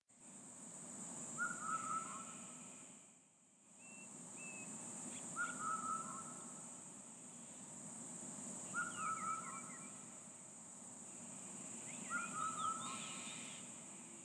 ある日の明け方，やたらと不思議な音がするのに気がついた． 音の強弱と間隔を大まかに書くと，
不思議な鳥の声(音量上げないと聞こえないかも）
英語ではCuckooだという．カッコーなら 日本でも実物の声何度も聞いたことある．まさに「カッコー」と聞こえる 声だから，全然違うよ．と言った．でも中国生れのカッコーの発声は 違うかも？
布谷鸟も嬉しそうに鳴きだしたではないか．だがまてよ， YouTubeの画面には四声杜鹃とあり，布谷鸟ではない． 鳴き声は確かに間違いなく，こちらで聞いたものと同じだ． それではと「布谷鸟とは」で検索かけて ひろいだす と「ホトトギス，ツツドリ，カッコウ，英語ではCuckooとも」とでてきた． 鳴き声をあたってみると こんな鳴き声らしい 四声杜鹃の声とは明らかに違うと言うべき． 似ているといえば言えないレベルかもしれないが． 「東京特許許可局」と聞こえるといわれるホトトギス のようだ． こちらの不思議鳥は明け方に鳴き，太陽が昇った頃には 鳴かなくなるようだ．